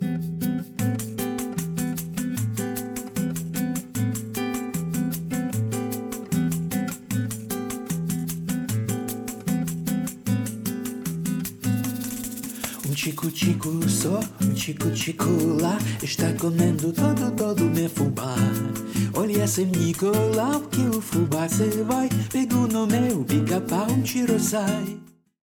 Такое впечатление, что включили автоматизацию панорамы, да ещё и сузили стерео у гитарки. Причины скорее всего в наложении частот и их маскирование, чтож ещё.